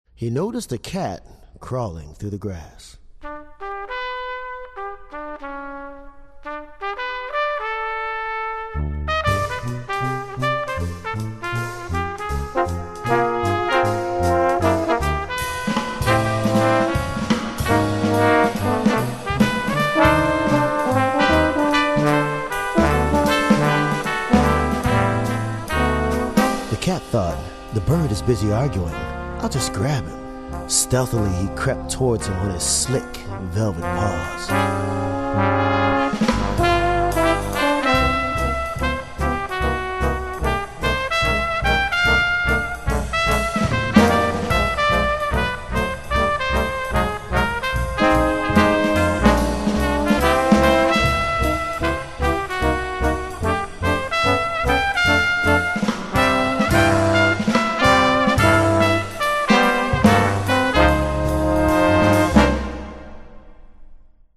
arranged Prokofievs's classic childrens work for 5 brass, one percussion, and a narrator